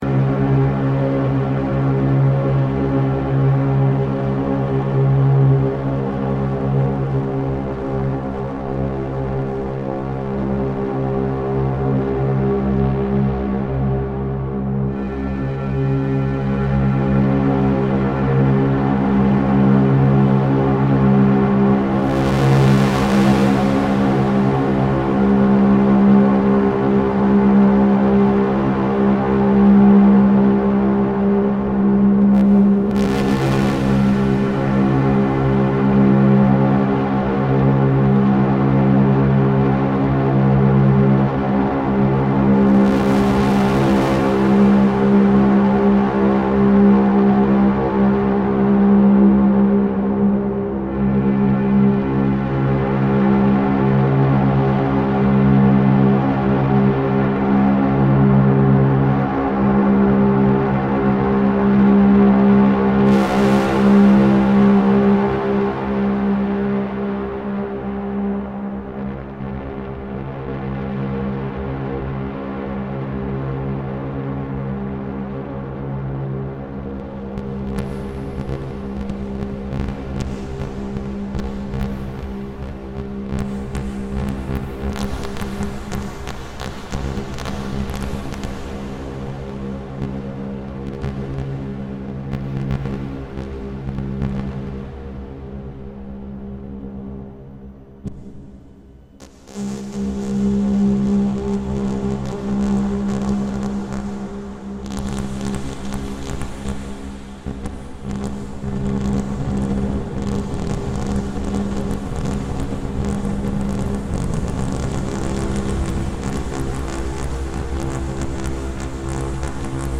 A bit of Elmyra v2 droning (voice 1 is gated by LFO 2, LFO 1 is sweeping its wavetable; voice 1’s envelope out goes to voice 2’s gate input; voices 3 and 4 are set to drone continuously). I’m using a shimmer down reverb from my Synthesis Technology Hyperion, and more reverb from Mood Mk2 along with a looping delay.